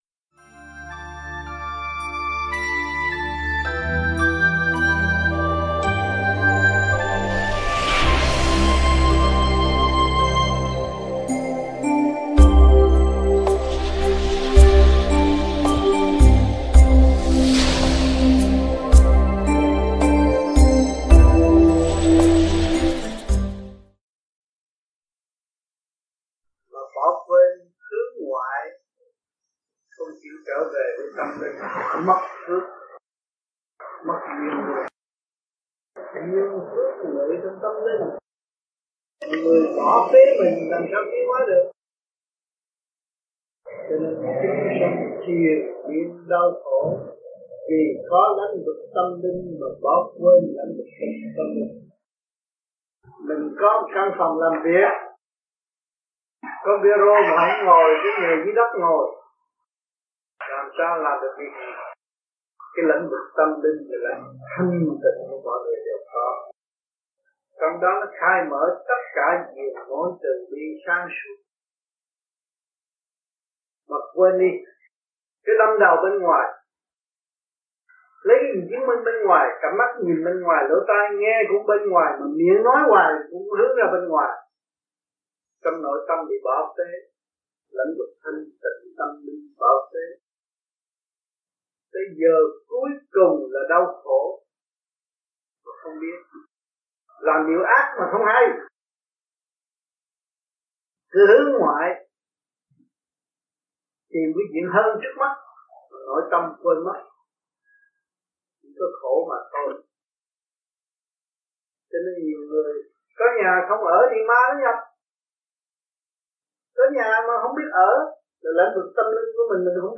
THUYẾT GIẢNG
VẤN ĐẠO